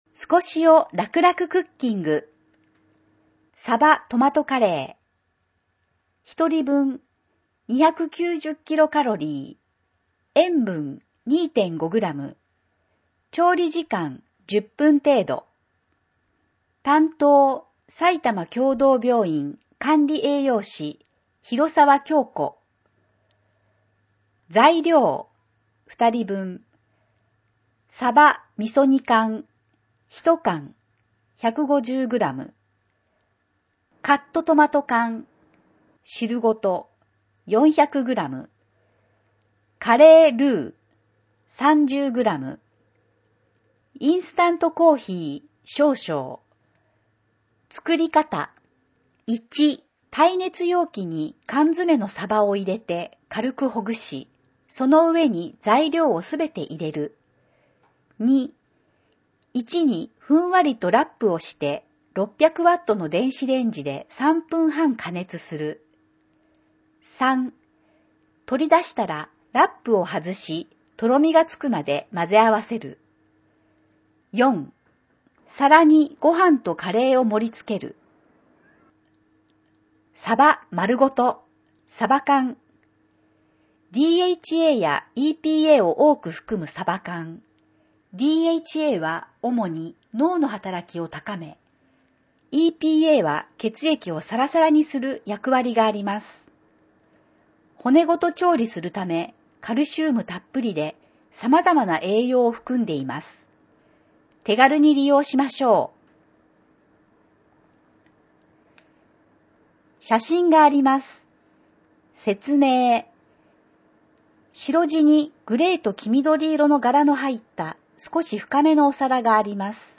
2022年3月号（デイジー録音版）